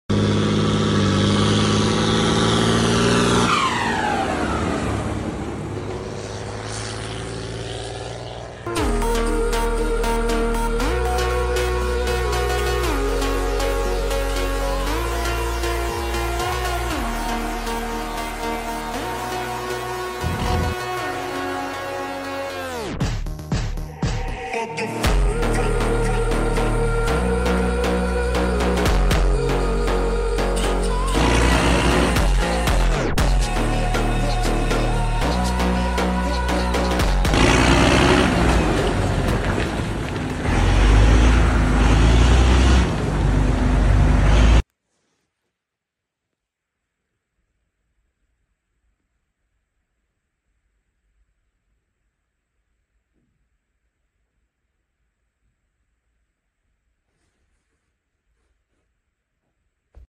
Fendt Favorit 615 LSA 🌽🚜 sound effects free download